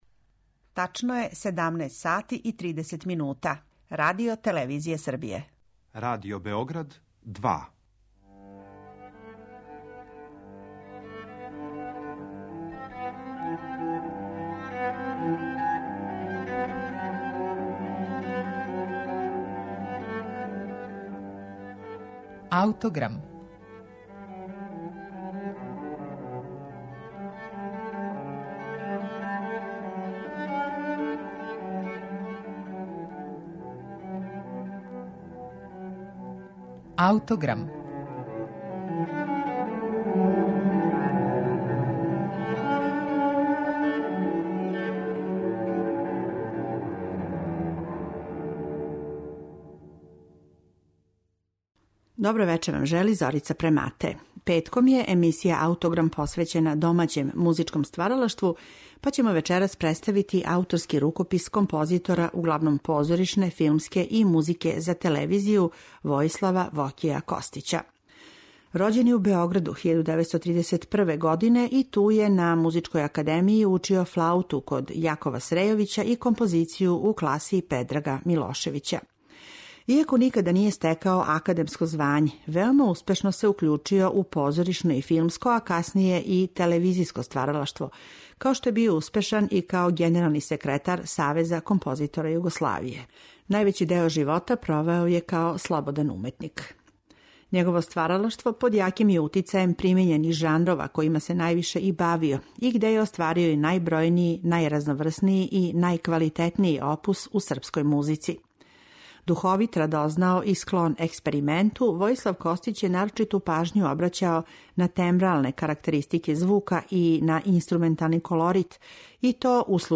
кантата